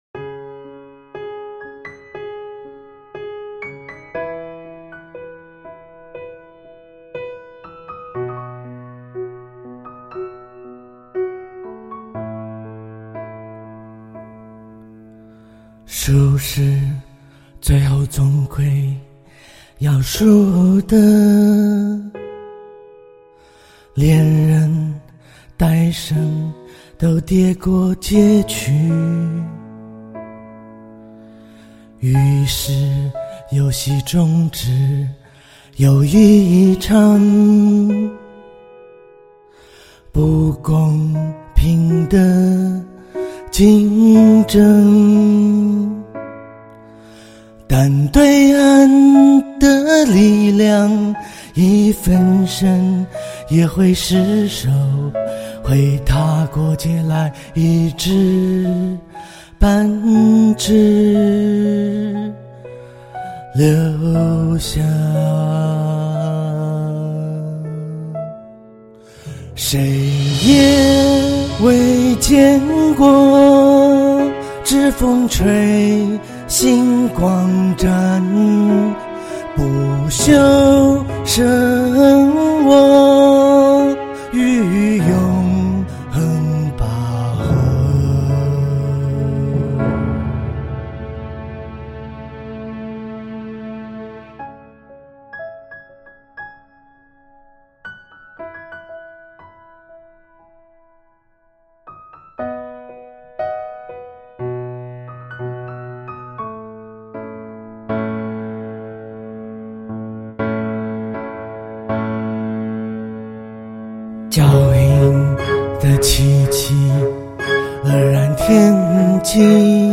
Chinese pop, Pop folk, Electropop/Technopop